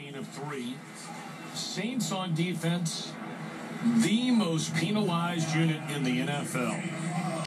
-Hate it when people say PEEEENalized instead of penalized like this:
Penalized1.m4a